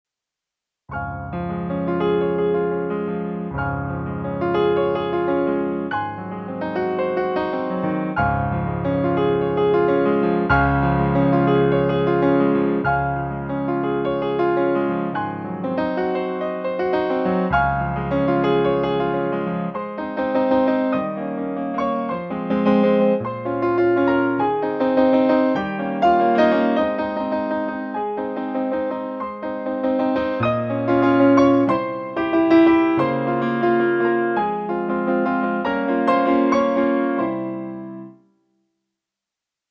Nein, liebes Kind (Instrumental)
14_nein_liebes_kind_instrumental.mp3